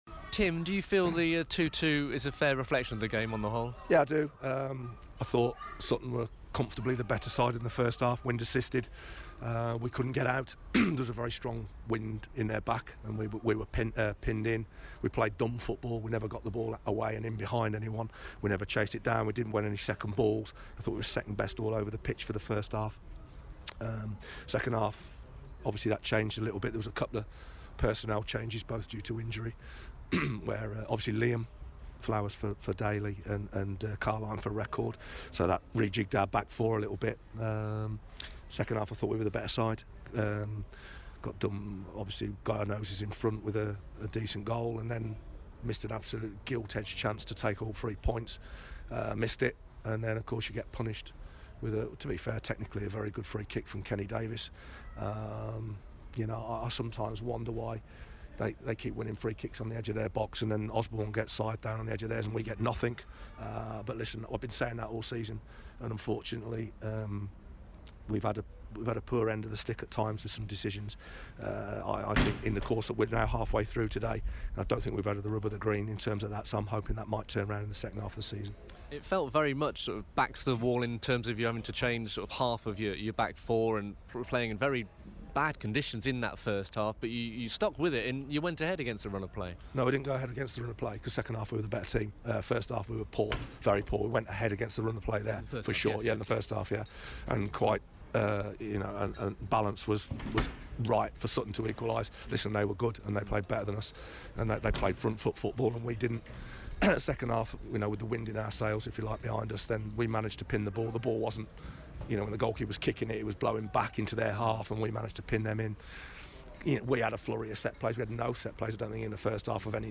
LISTEN: Solihull Moors boss Tim Flowers reacts to their 2-2 draw at Sutton United